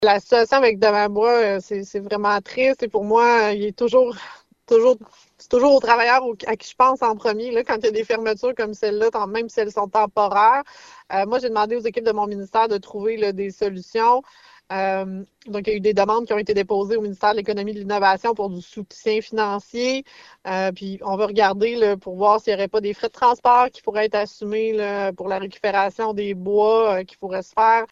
La ministre responsable de la région gaspésienne et ministre des Ressources naturelles et des Forêts signale que de l’aide est envisagée :